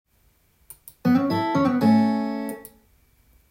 コードAで使えるスタジオミュージシャンフレーズ５選
スタジオ系のミュージシャン　みんなが使ってそうなフレーズばかりです。
このように全てのフレーズは、コードトーンと言われるコードの構成音を主体に